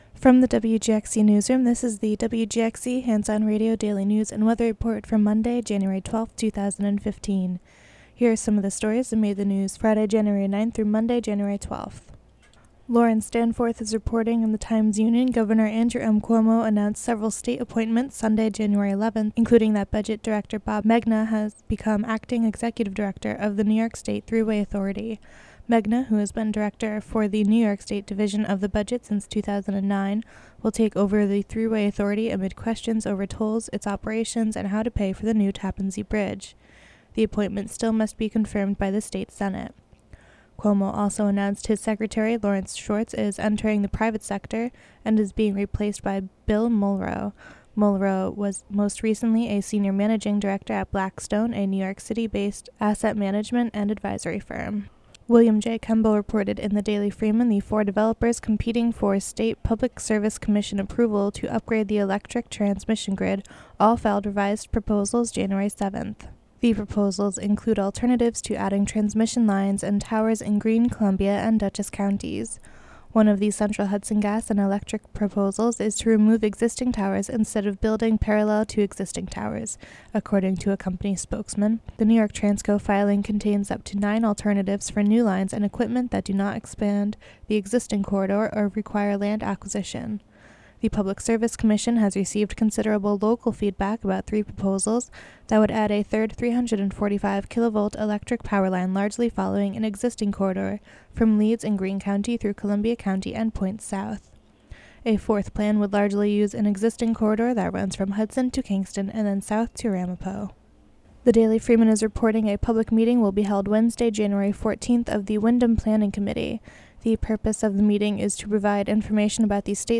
Local news and weather for Monday, January 12, 2015.